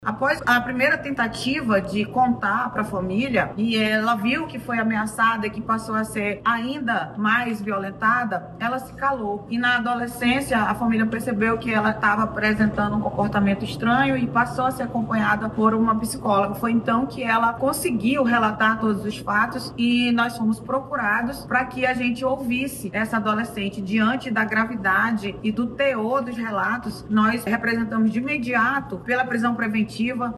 Ainda segundo a delegada, somente após passar por acompanhamento psicológico, a menor conseguiu relatar, novamente, os fatos e o caso chegou ao conhecimento da Polícia.